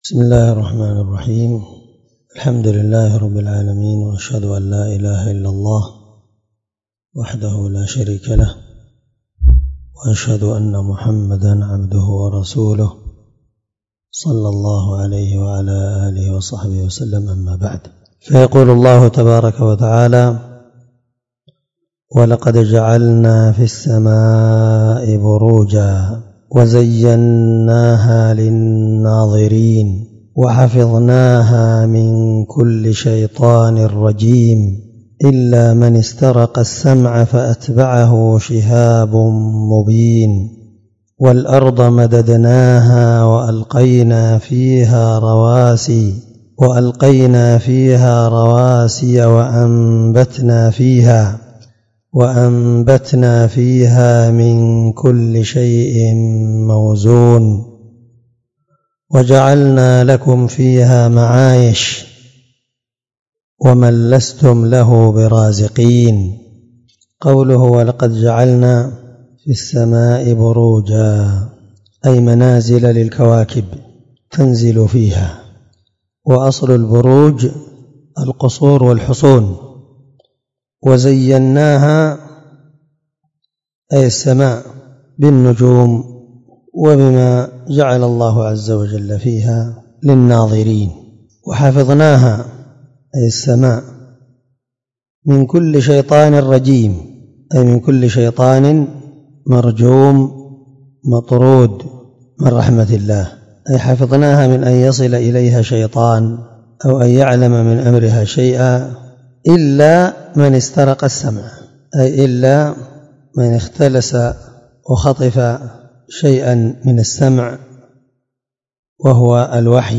713الدرس4 تفسير آية (16-20) من سورة الحجر من تفسير القرآن الكريم مع قراءة لتفسير السعدي
دار الحديث- المَحاوِلة- الصبيحة.